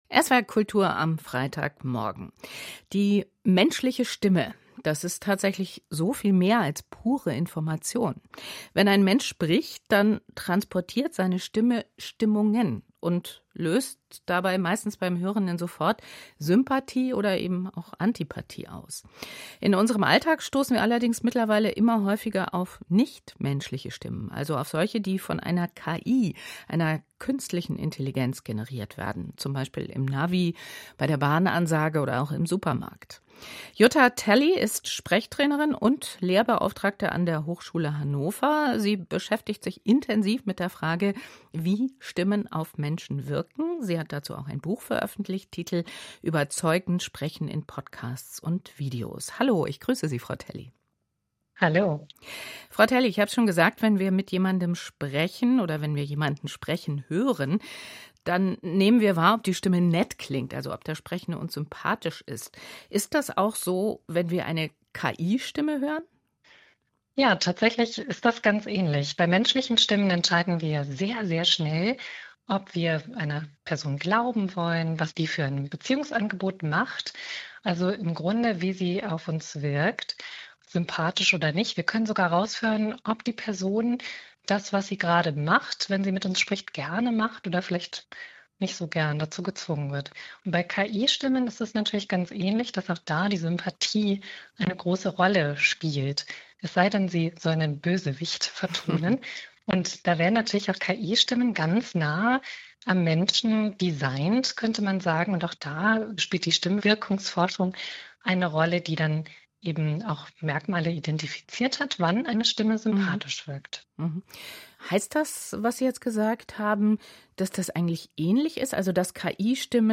Zum Thema wurde ich vom SWR-Kultur am Morgen interviewt. Das Interview wurde am 22.8.2025 ausgestrahlt: